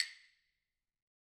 Claves1_Hit_v3_rr1_Sum.wav